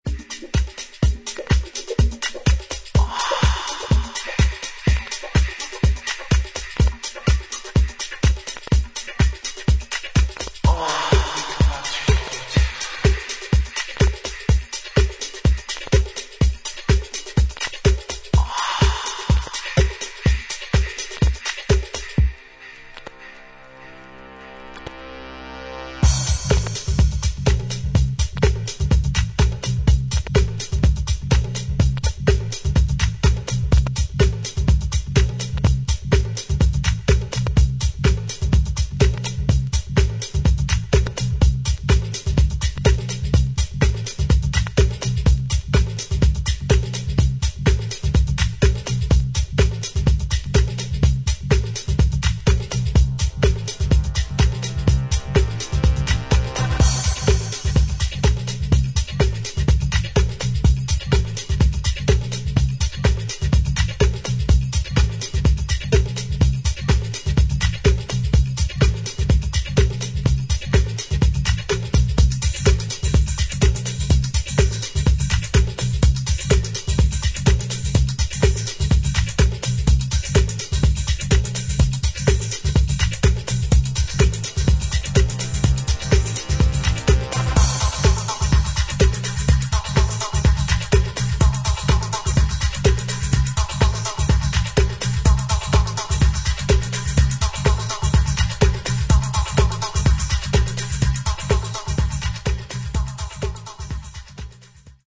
針飛びはしませんがノイズあり。
ダイナミックで粗削りな音作りが魅力の、90's UKアンダーグラウンドハウス隠れた傑作。